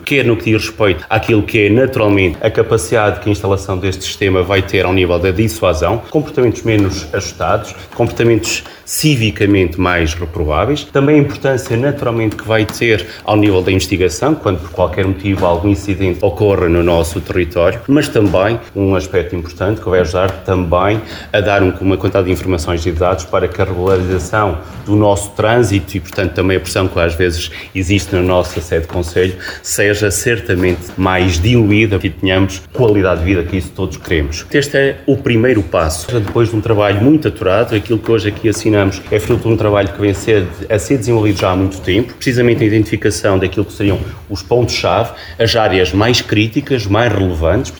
Alexandre Favaios presidente da Câmara de Vila Real, diz que este protocolo não é para resolver problemas, mas para os prevenir.